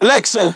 synthetic-wakewords
ovos-tts-plugin-deepponies_Franklin_en.wav